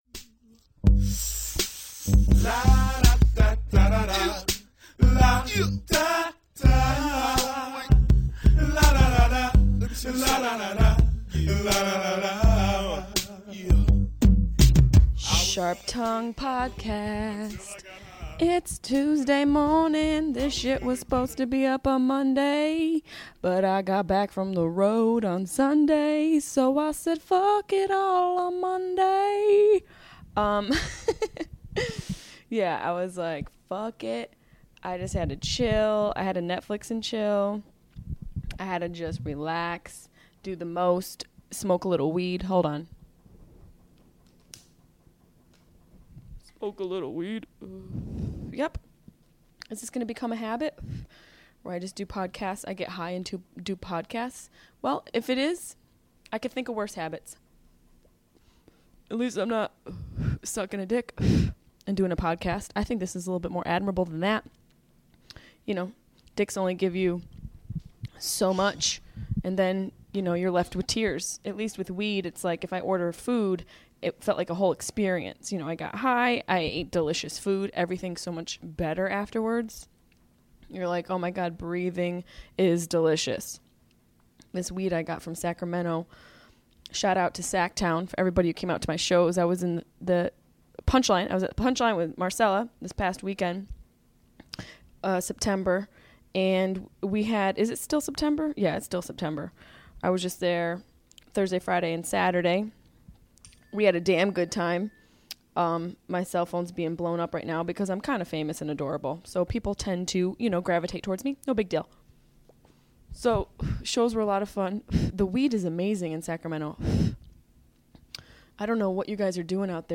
This week i do my podcast high and alone.